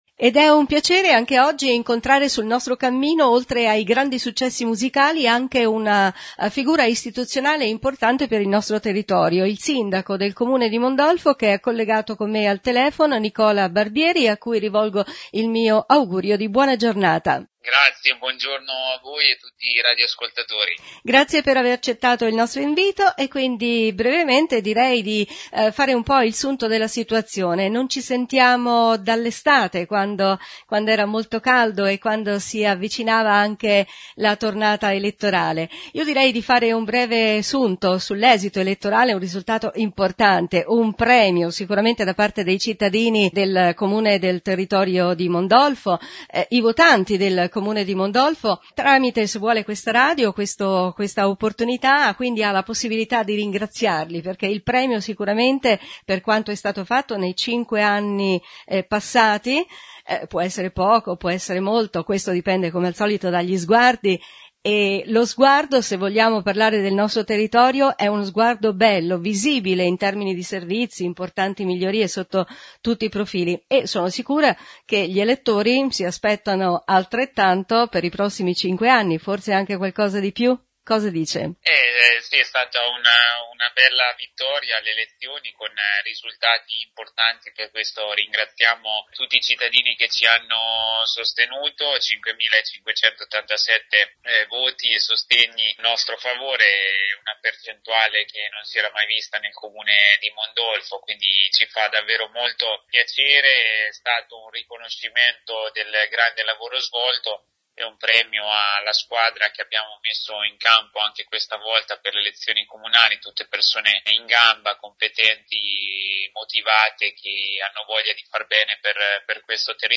New Radio Star | Nicola Barbieri Sindaco del Comune di Mondolfo risponde …….